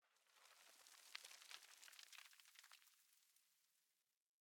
sand20.ogg